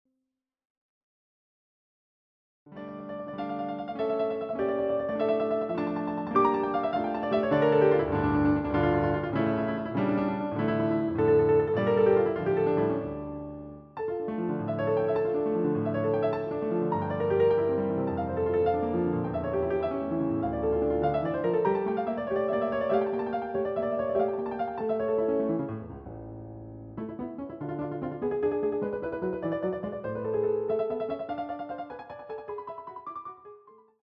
CD quality digital audio Mp3 file
using the stereo sa1mpled sound of a Yamaha Grand Piano.